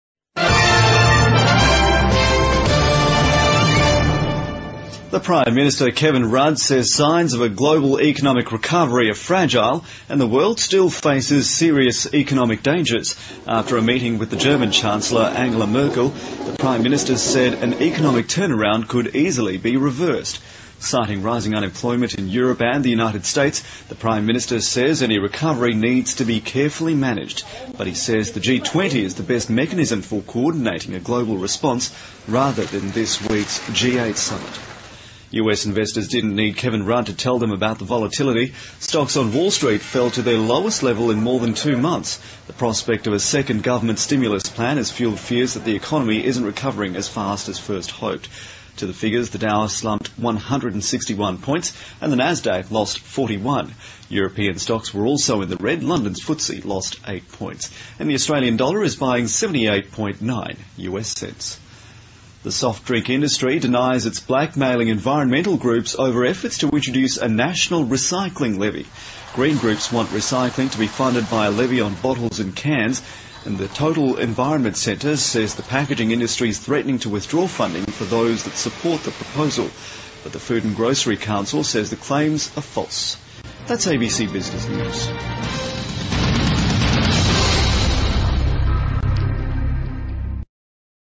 澳洲新闻 2009-07-08 听力文件下载—在线英语听力室